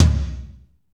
Index of /90_sSampleCDs/Northstar - Drumscapes Roland/DRM_Slow Shuffle/KIT_S_S Kit 1 x
TOM S S L0WL.wav